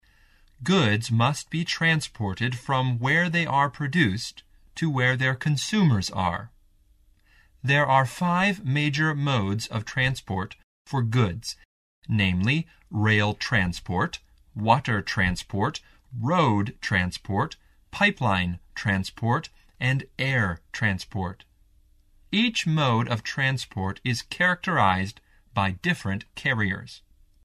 课文朗读